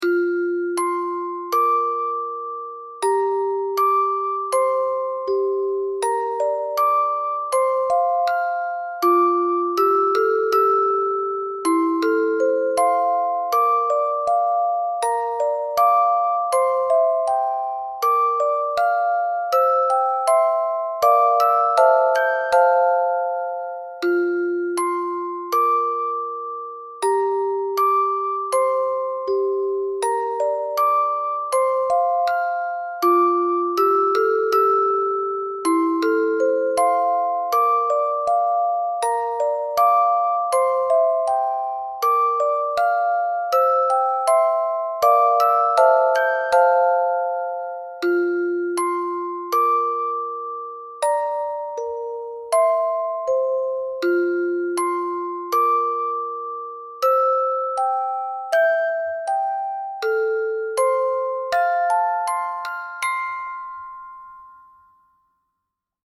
綺麗な音色で気に入りました．